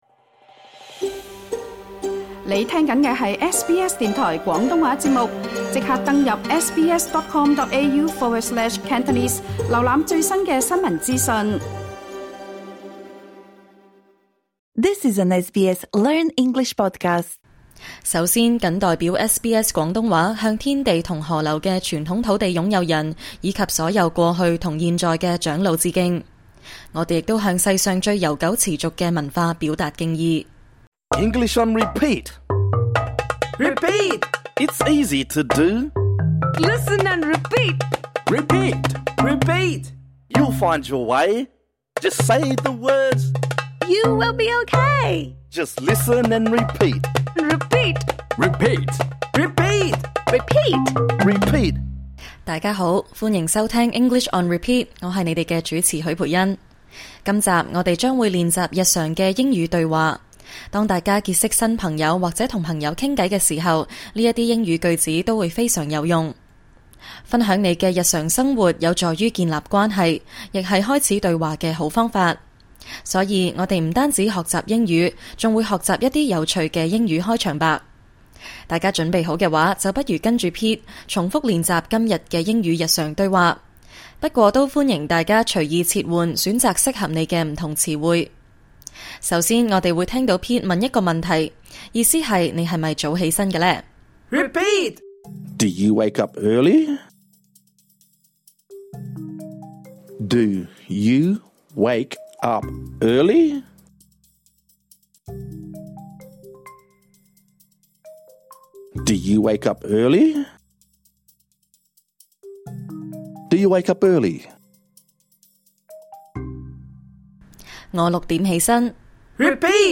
這個教學專為初學者而設。